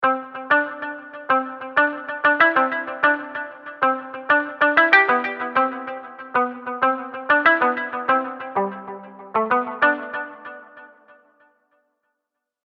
Guitar – Alone
Guitar-Alone.mp3